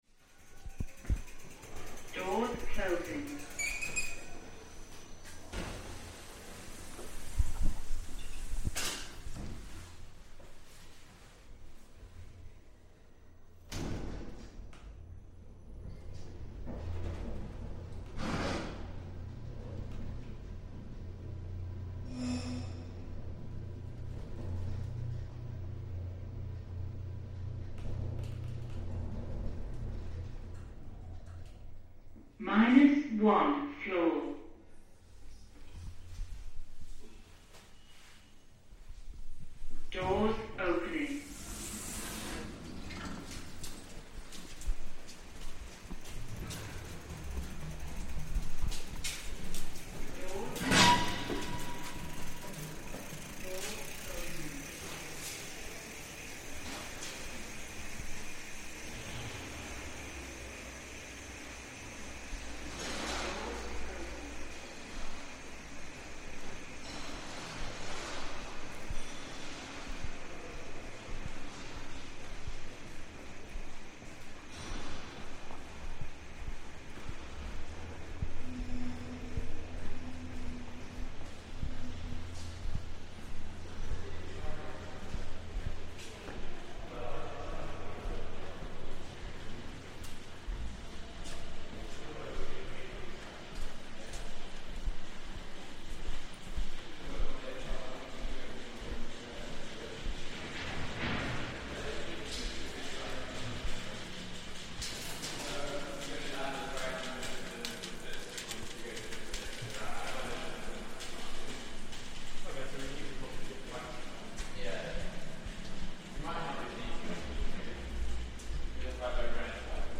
Headliner Embed Embed code See more options Share Facebook X Subscribe A walk along the Greenwich foot tunnel right underneath the River Thames.